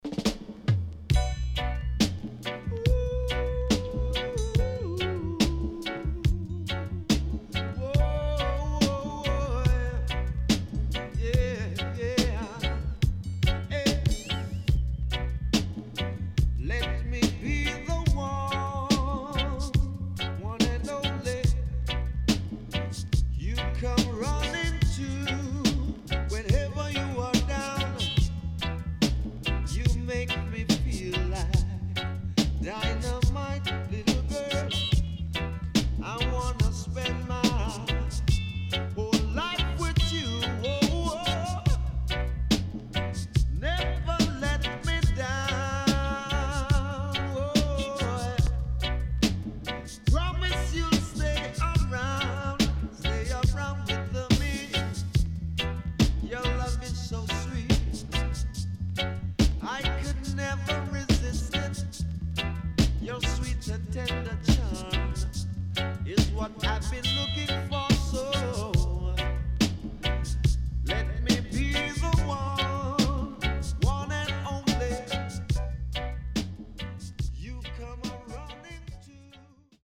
HOME > LP [DANCEHALL]  >  SWEET REGGAE  >  定番70’s
SIDE B:少しチリノイズ入りますが良好です。